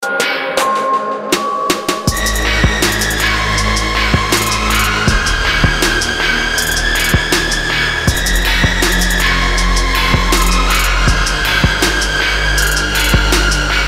Подскажите ,пожалуйста,как накрутить такой лид в мэссиве(можно в другом плагине),знаю что надо осцеляторы расстроить чутка,ревер добавить,но такой визжазжий звук не получается,как тут: